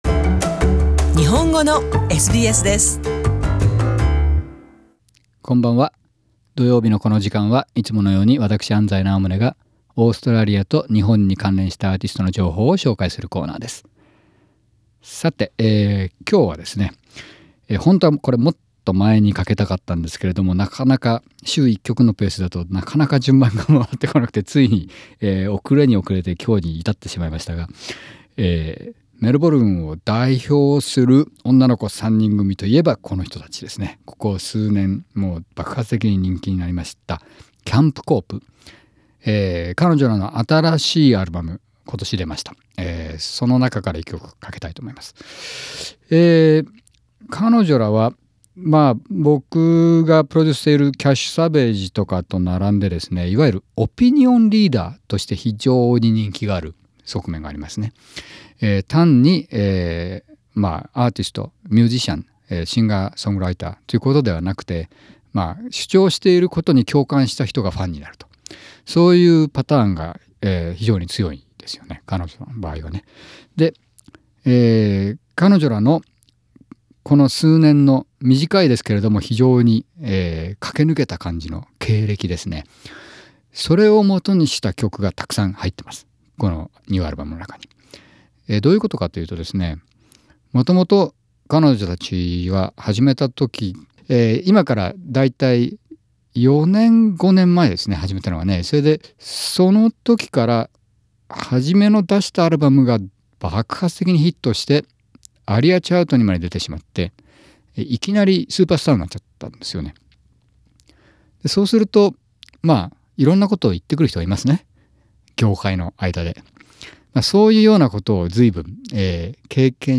Camp Cope are an Australian alternative rock trio from Melbourne.